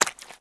STEPS Swamp, Walk 25.wav